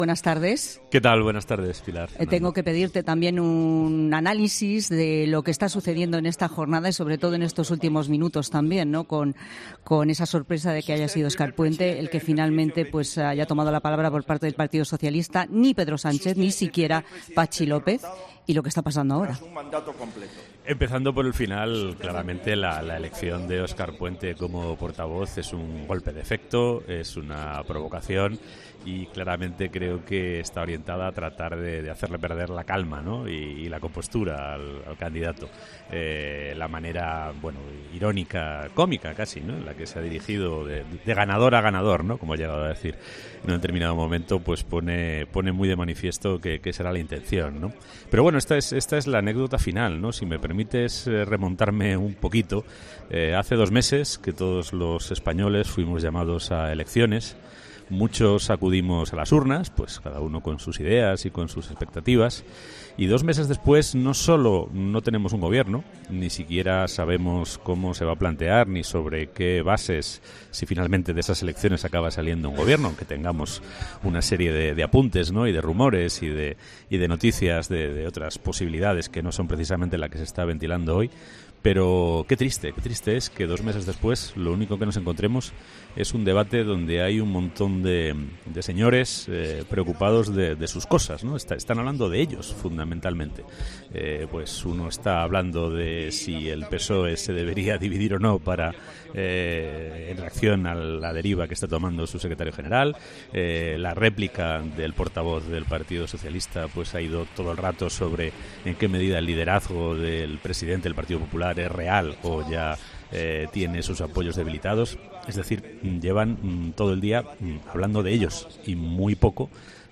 Y ha arrancado con una aparición 'estelar': la de la aparición de Óscar Puente en la tribuna del Congreso de los Diputados. Lorenzo Silva, colaborador de 'La Tarde', ha realizado un análisis de lo que está sucediendo en la Cámara Baja.